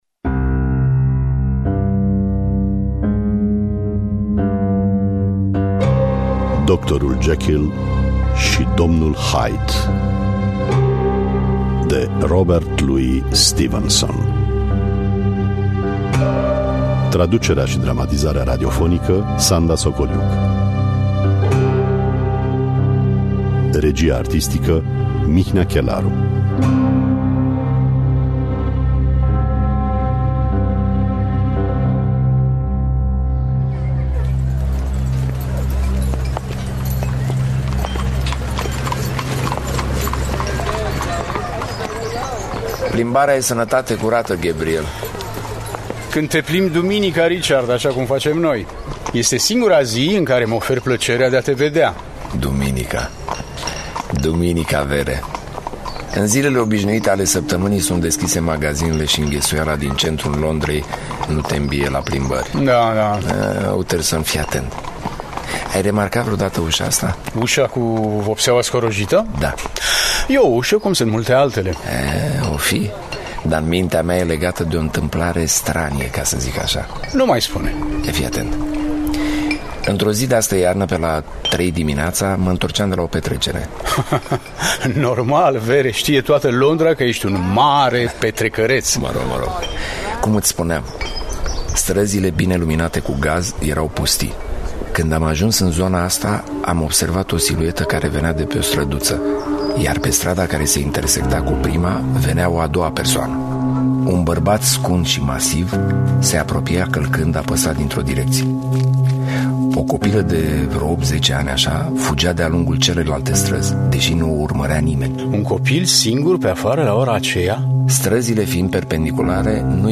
Traducerea şi dramatizarea radiofonică